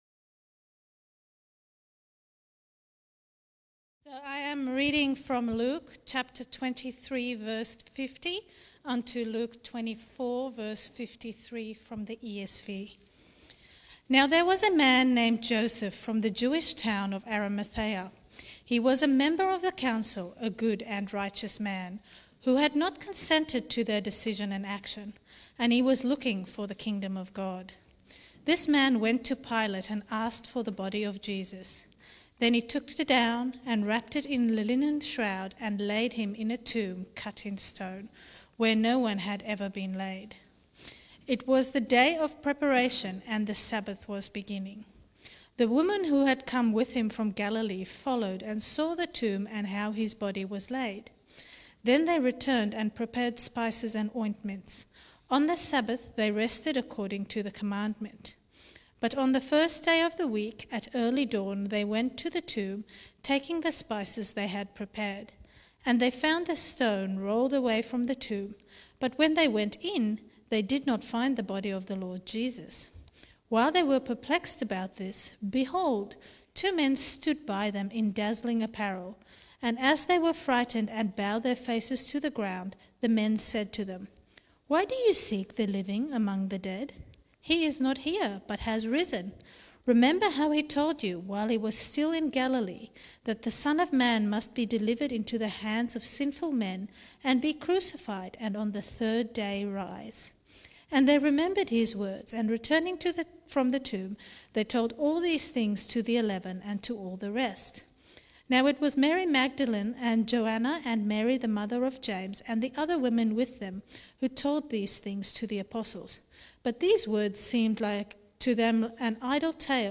Sermons | Wonga Park Christian Reformed Church